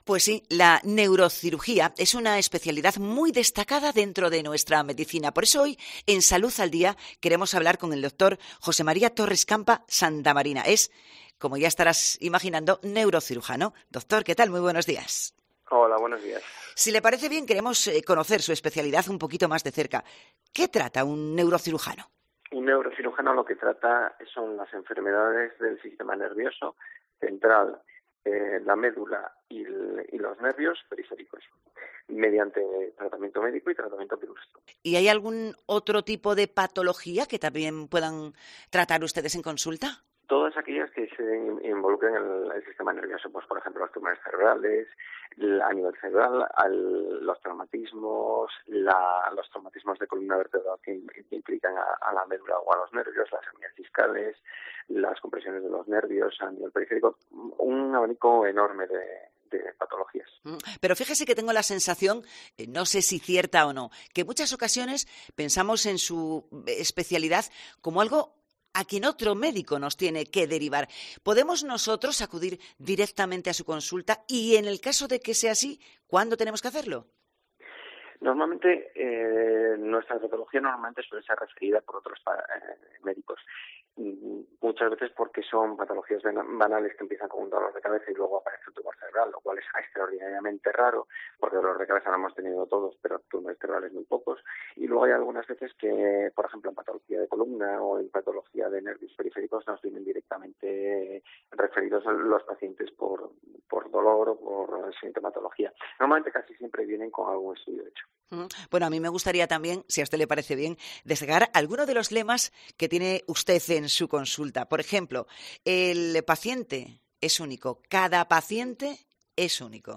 Salud al Día: entrevista